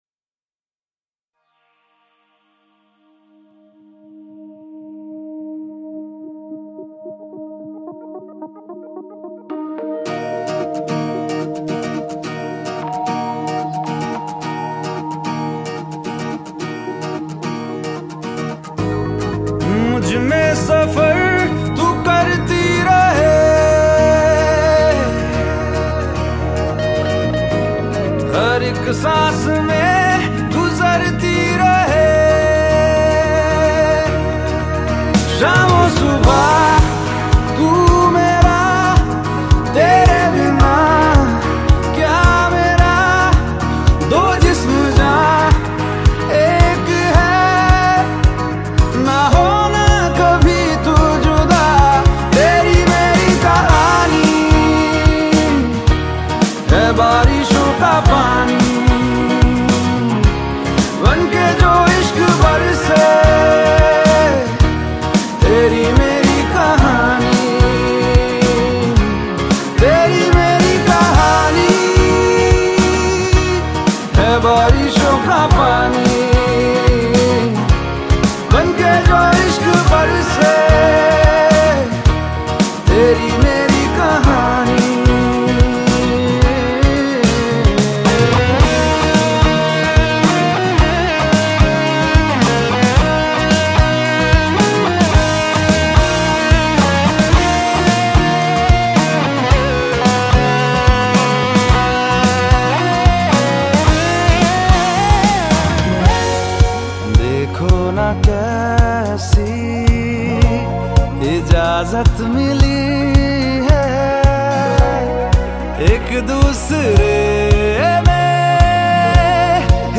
It delivers a sound that feels both fresh and consistent.